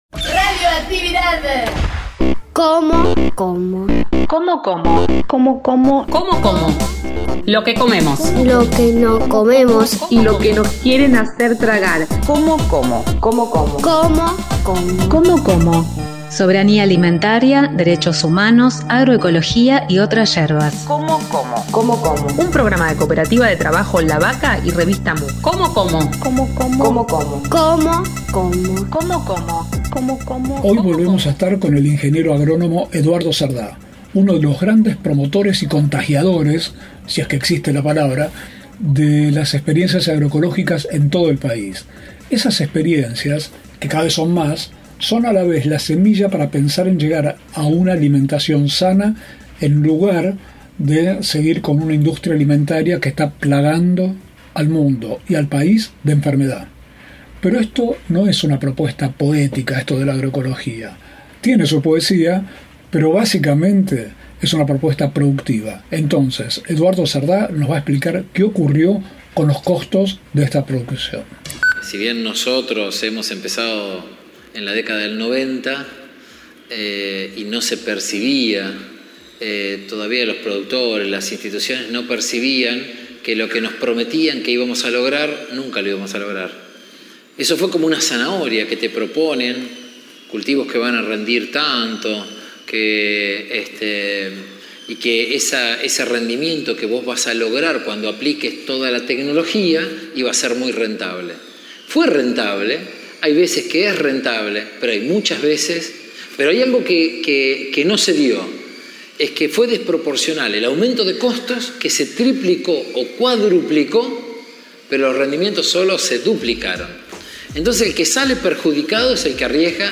Volvemos a conversar con el ingeniero agrónomo